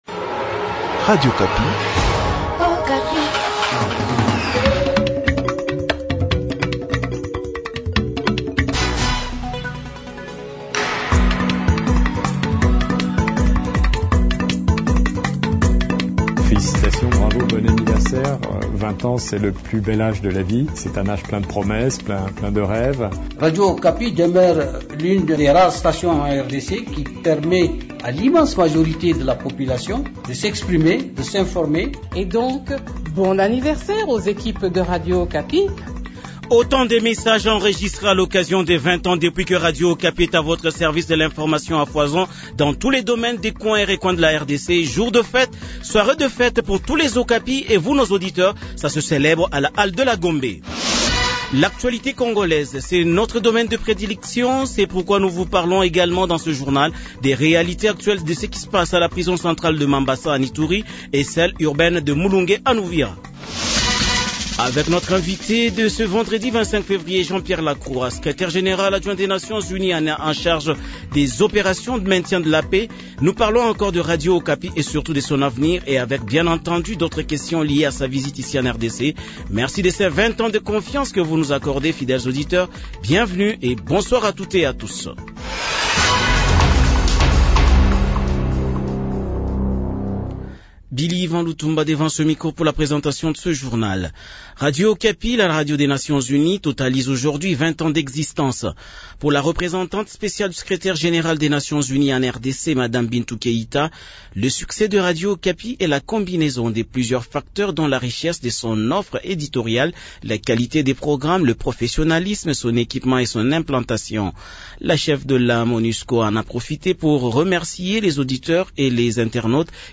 Journal 18h Vendredi 25/02/2022